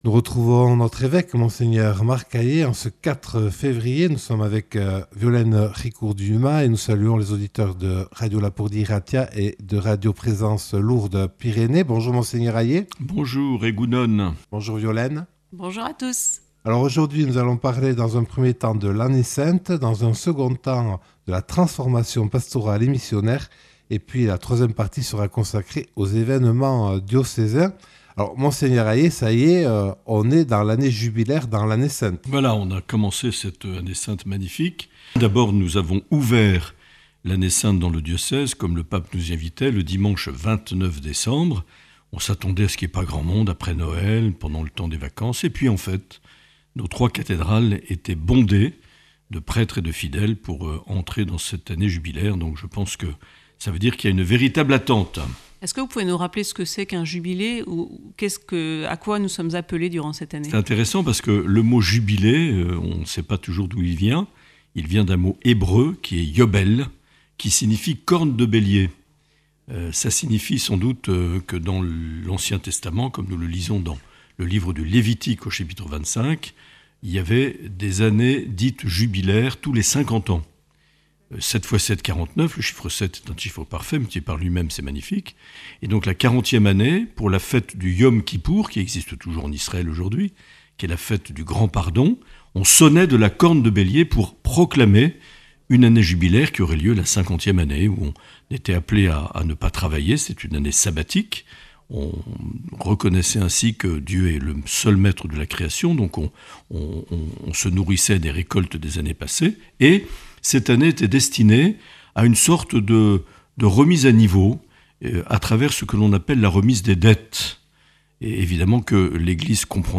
Dans cet entretien enregistré le 4 février 2025, Mgr Marc Aillet répond aux questions de Radio Lapurdi et de Radio Présence Lourdes Pyrénées.